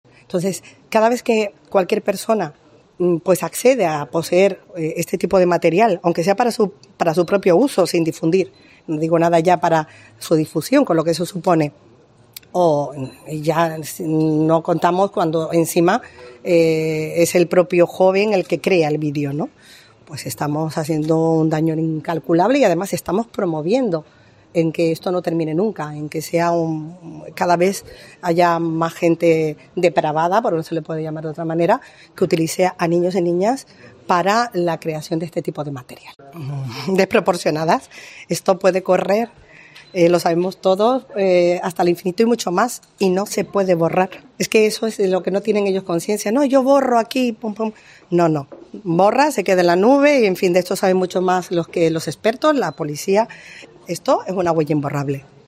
La magistrada de menores Reyes Martel valora el caso de los canteranos del Real Madrid